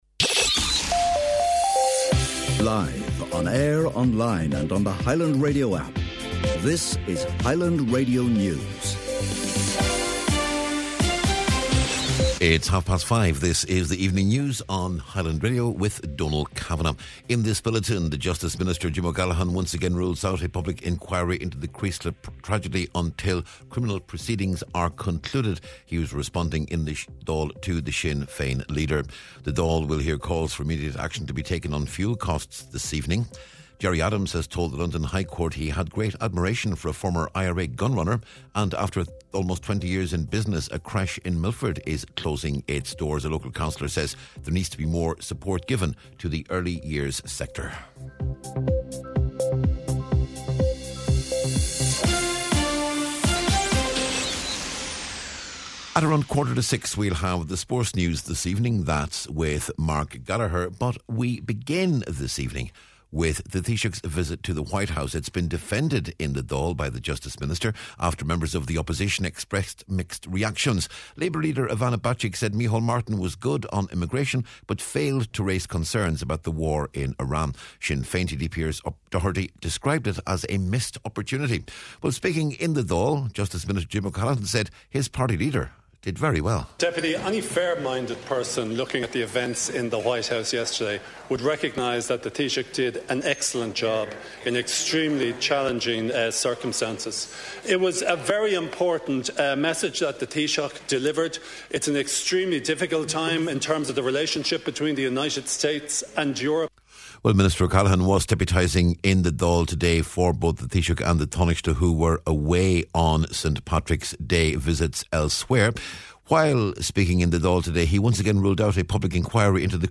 News, Sport and Obituaries on Wednesday March 18th